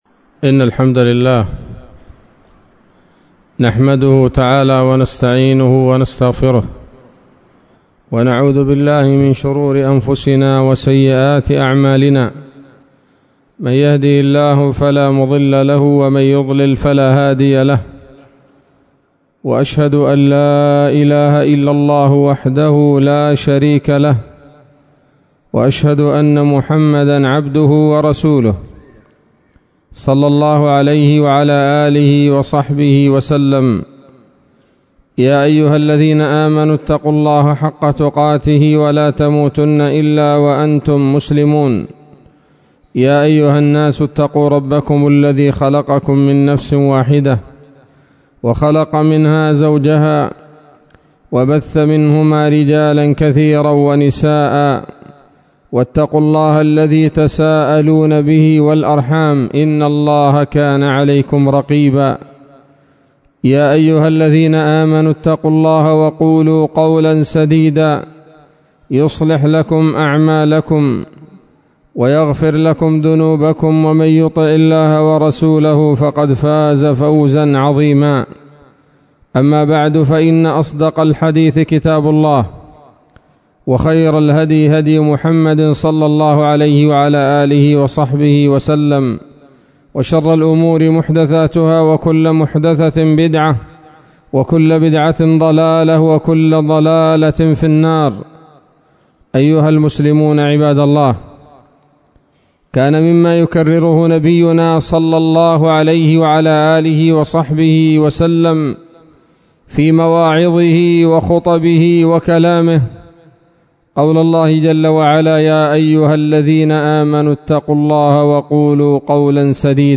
خطبة جمعة بعنوان: (( حصائد الألسن [1] )) 1 شعبان 1443 هـ، دار الحديث السلفية بصلاح الدين